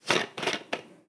Abrir un tapón de rosca 1
Cocina
Sonidos: Hogar